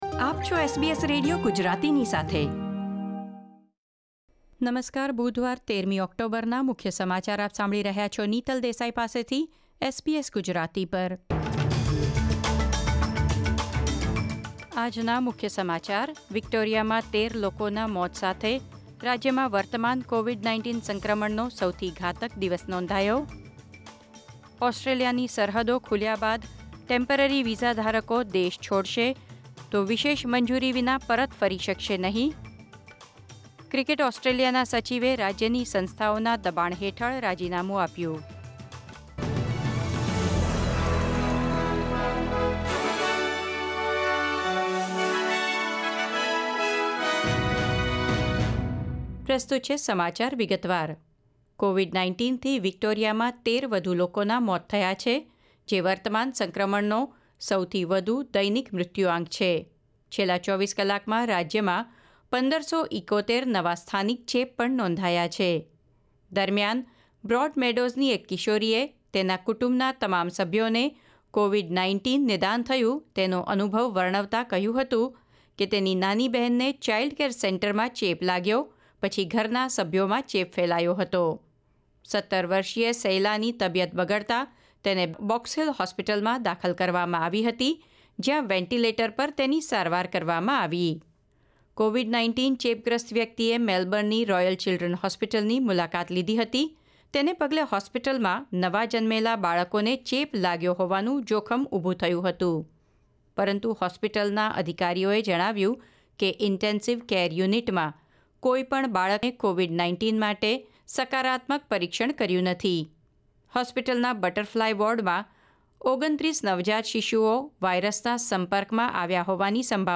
SBS Gujarati News Bulletin 13 October 2021